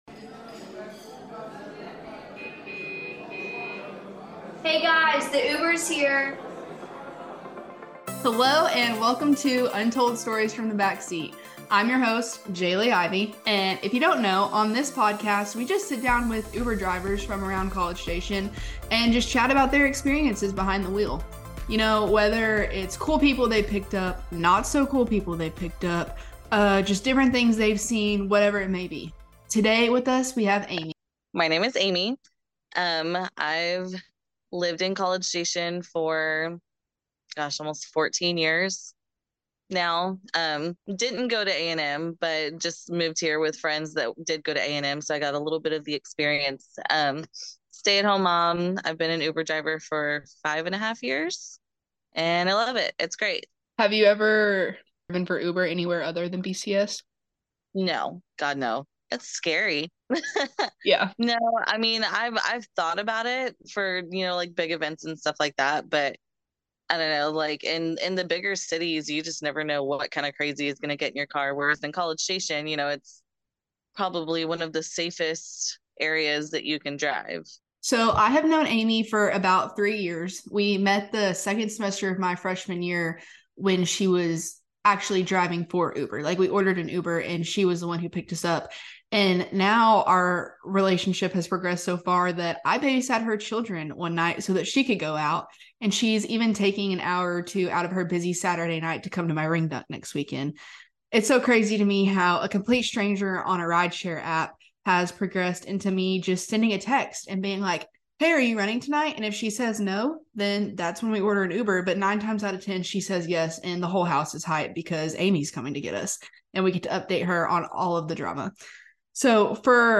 Welcome to Untold Stories from the Backseat! Here we will chat with local rideshare drivers and dive into what it’s like to pick up strangers.